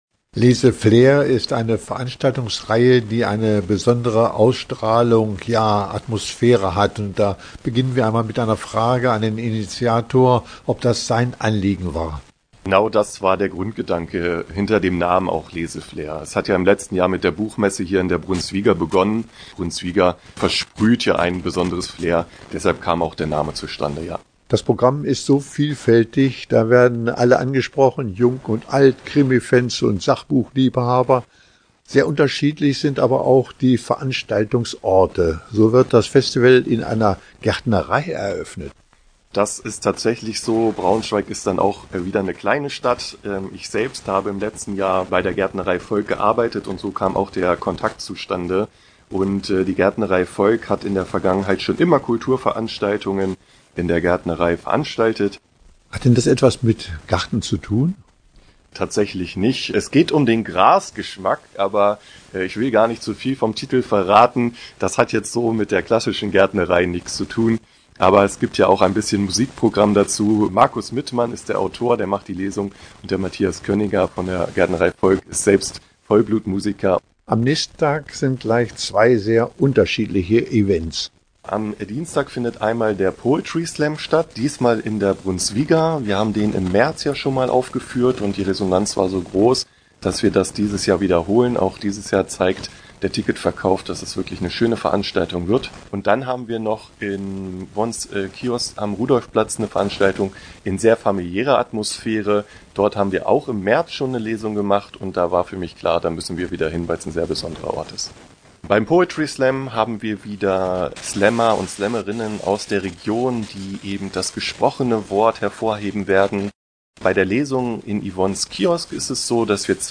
Interview-Leseflair-2025.mp3